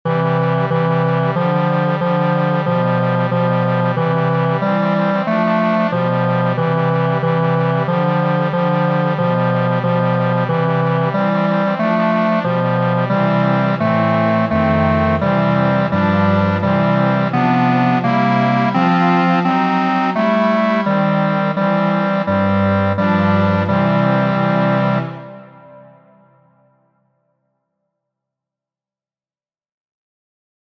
Muziko:
Studo por gitaro, de Francisco Tárrega, orĥestrigita.